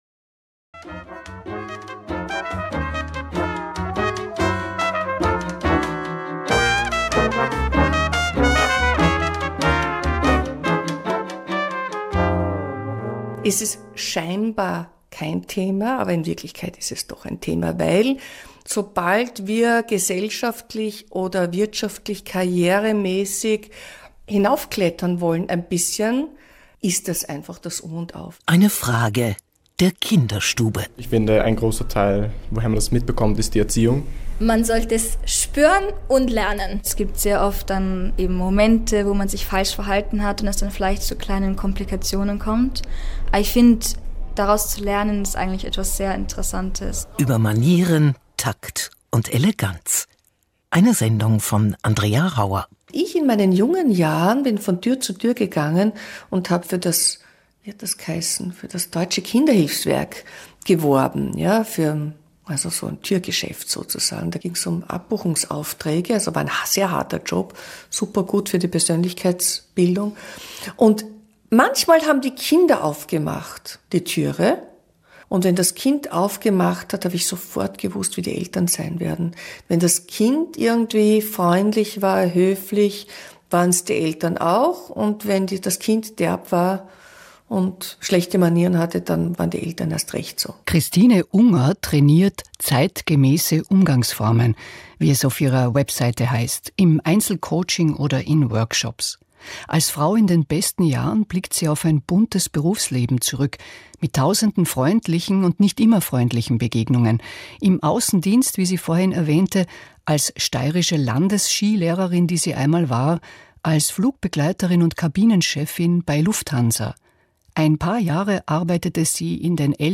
Radiointerview aus der Sendung „Moment“ auf Ö1 vom 23. April 2024